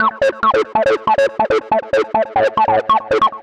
Index of /musicradar/future-rave-samples/140bpm
FR_Cheeka_140-A.wav